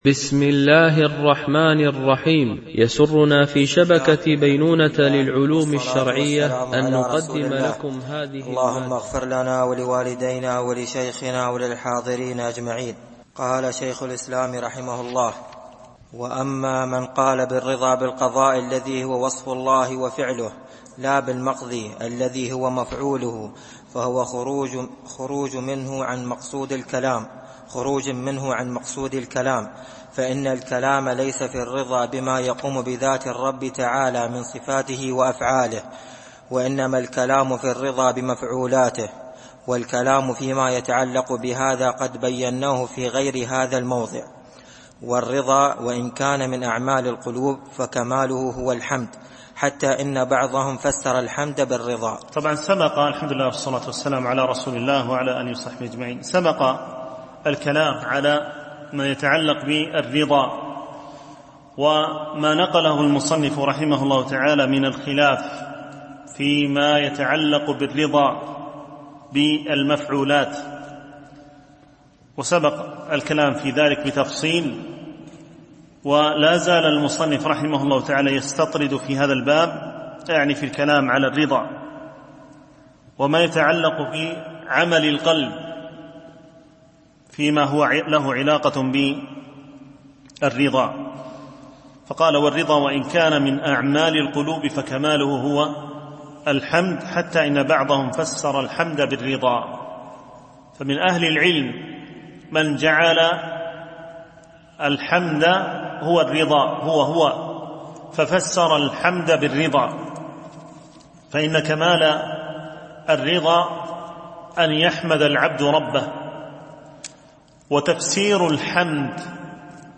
شرح التحفة العراقية في الأعمال القلبية ـ الدرس 11 (الصفحة 361 - 371)